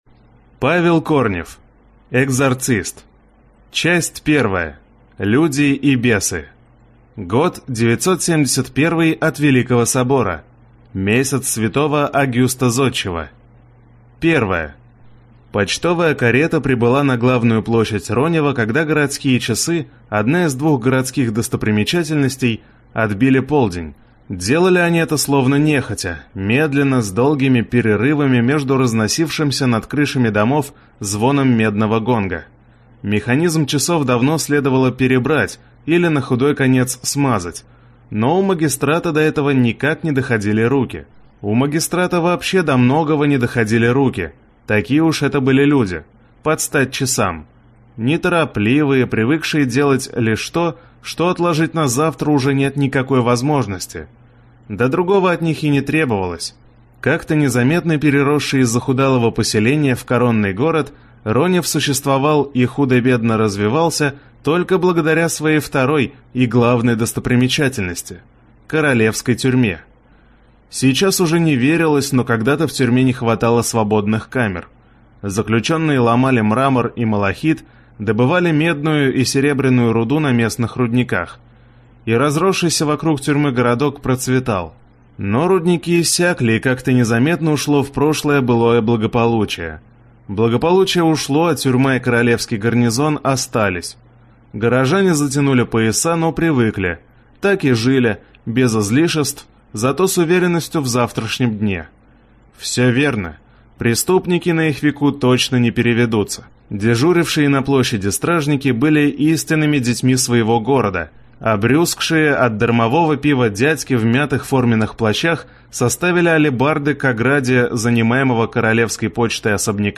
Аудиокнига Экзорцист | Библиотека аудиокниг
Прослушать и бесплатно скачать фрагмент аудиокниги